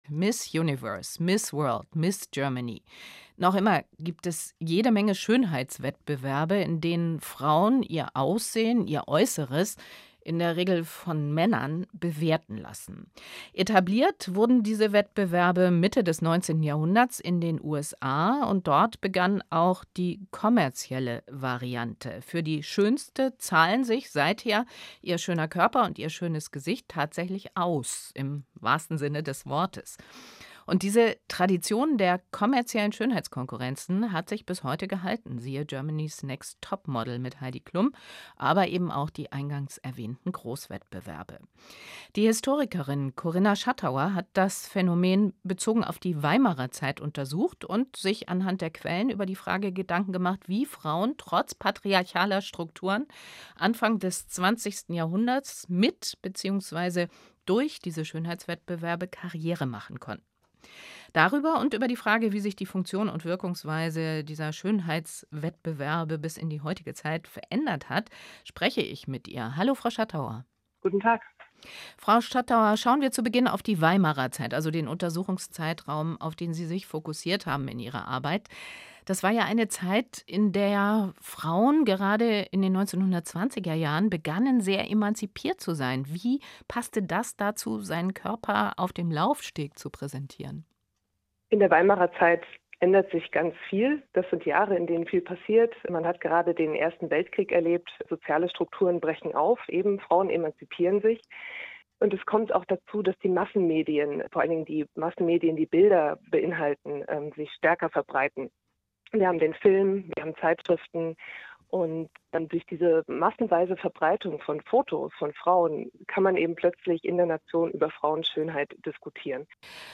SWR-Interview